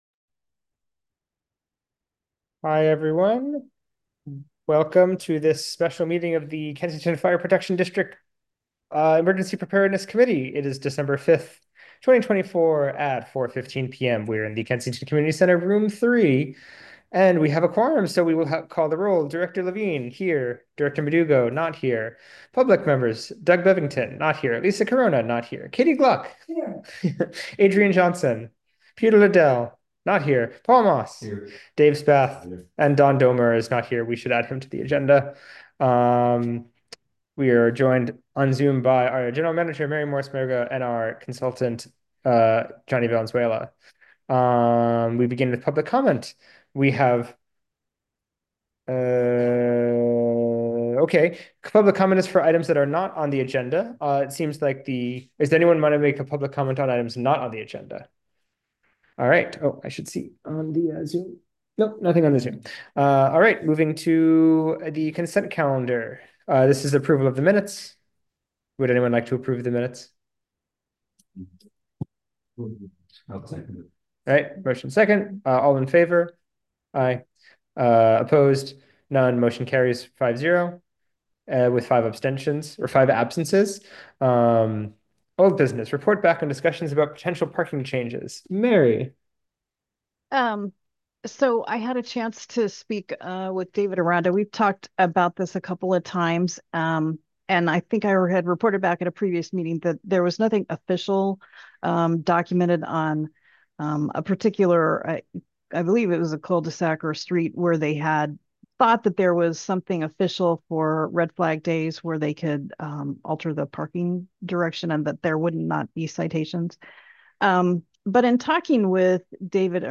Emergency Preparedness Committee Meeting